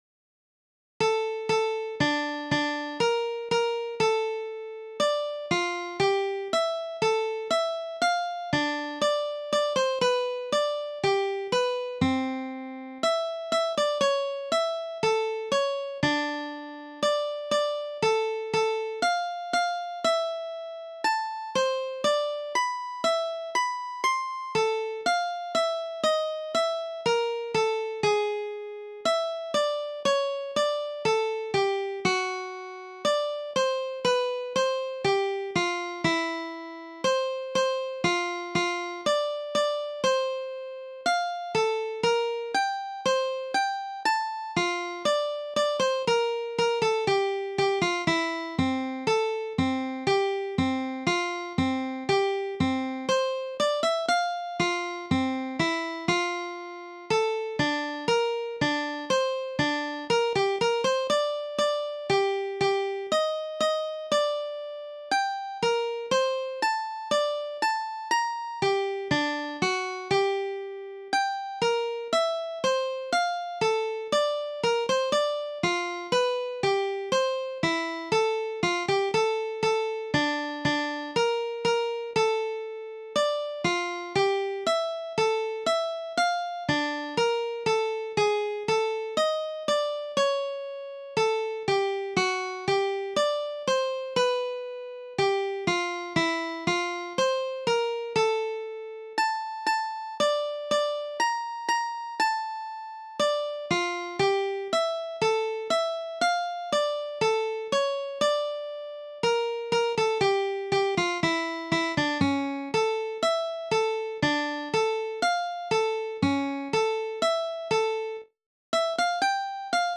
DIGITAL SHEET MUSIC - MANDOLIN SOLO